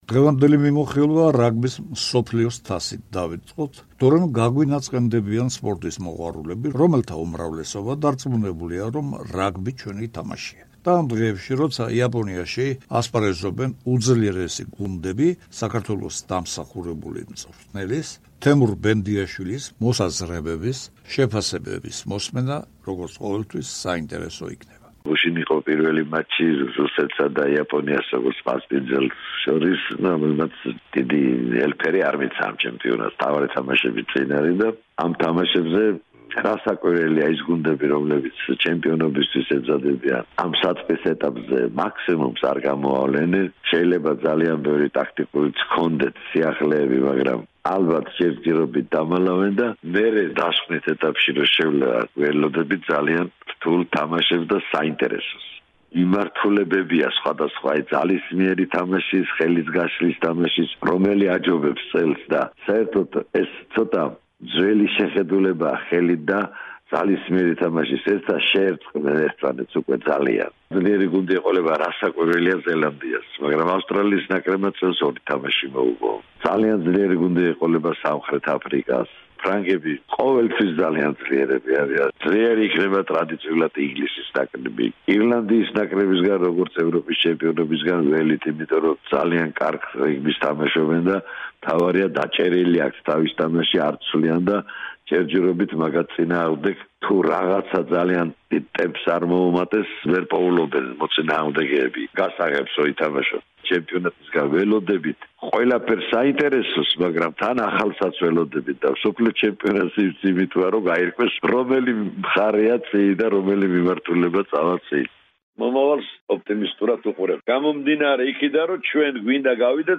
დიდი რაგბი იაპონიაში დღევანდელ სპორტულ გადაცემაში: